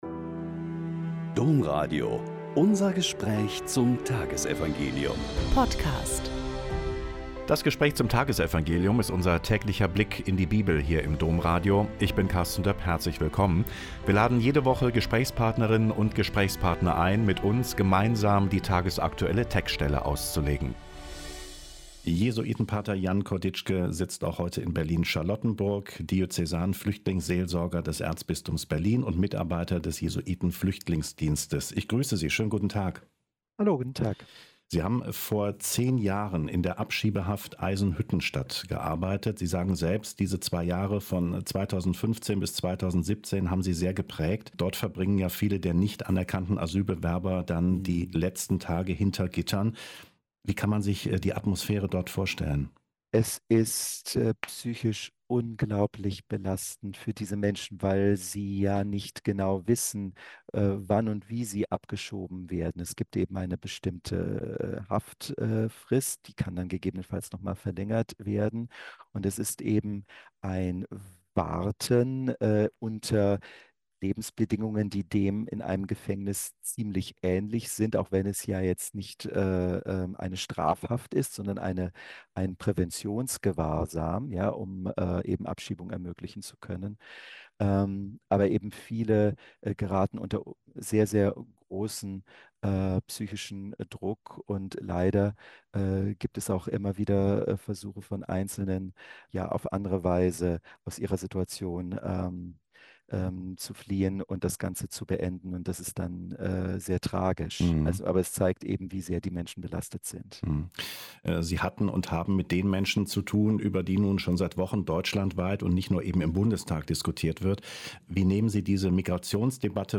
Mk 8,27-33 - Gespräch